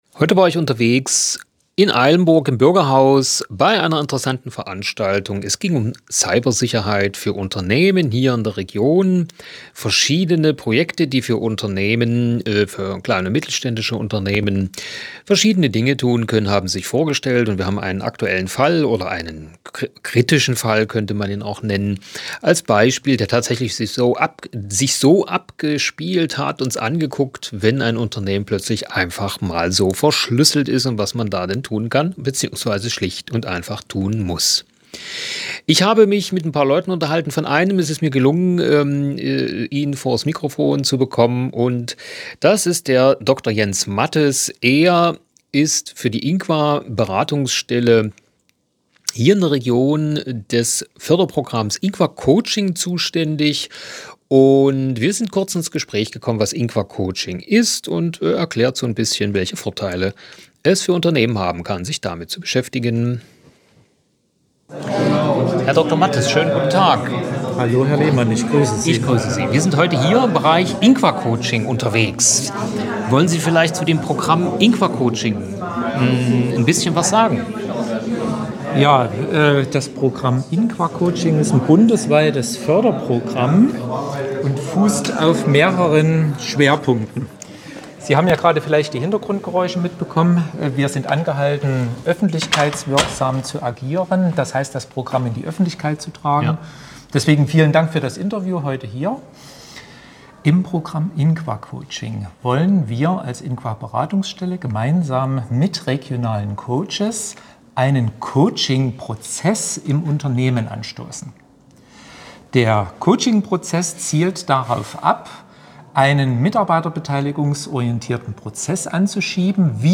IT- und Cybersicherheit standen heute im Fokus einer Veranstaltung vom Mittelstand-Digital Zentrum Leipzig-Halle mit weiteren Ausstellern rund um die Veranstaltung im kleinen Saal vom Bürgerhaus.